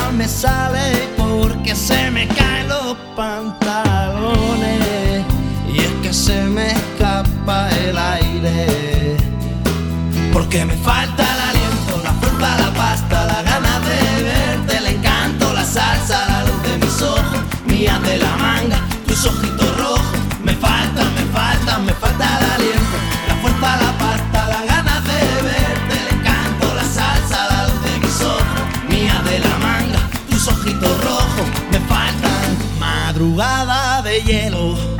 Pop Latino